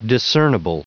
Prononciation du mot discernable en anglais (fichier audio)
Prononciation du mot : discernable